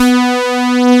Index of /90_sSampleCDs/E-MU Producer Series Vol. 2 – More Studio Essentials/KeysSprints/Saw Synth + OBX
OBX SAWS B3.wav